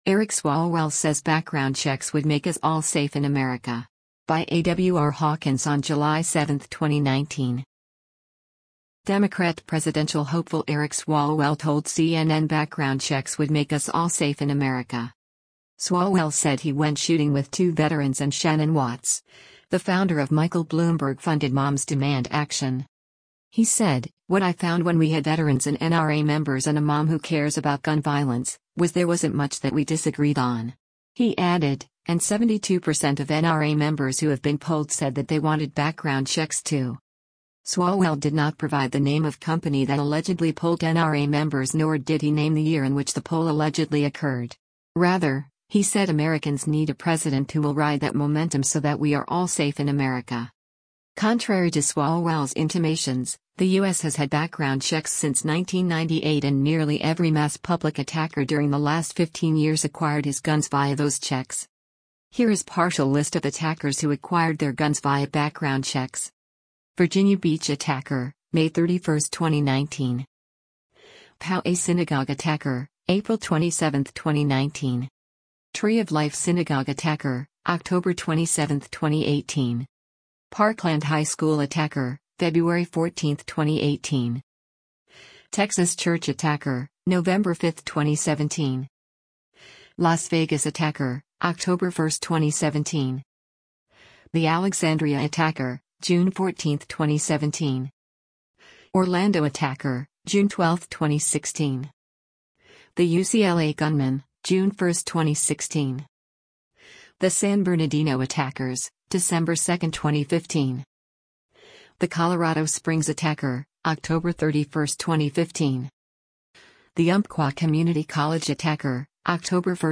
Democrat presidential hopeful Eric Swalwell told CNN background checks would make us “all safe in America.”